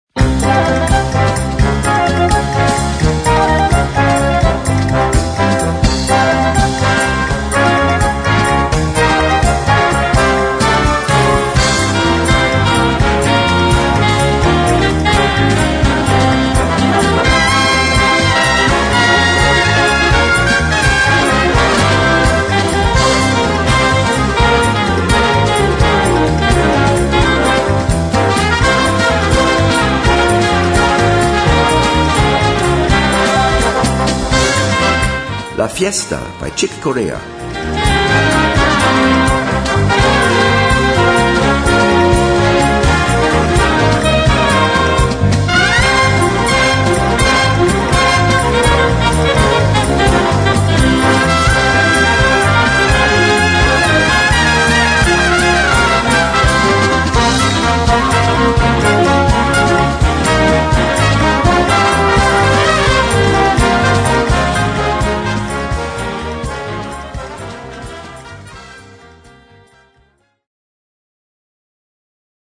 Noten für Blasorchester, oder Brass -, or Big Band.